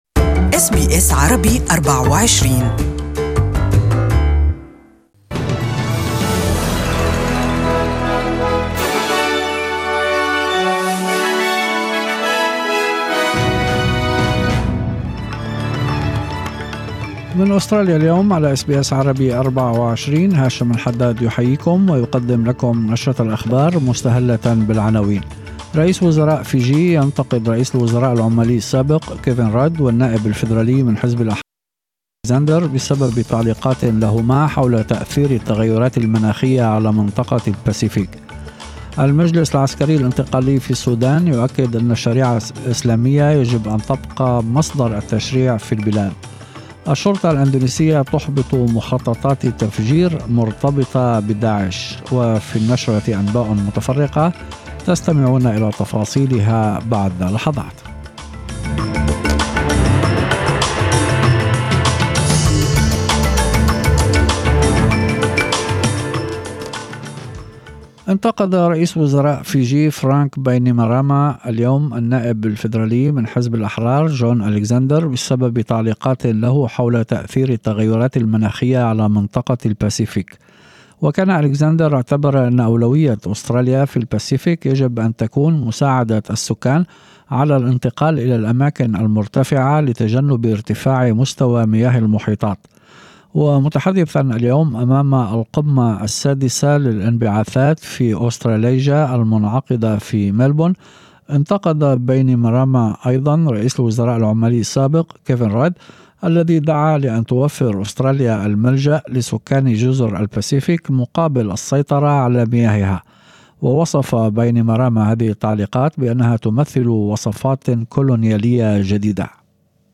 The News Bulletin: Fijian PM to call for climate leadership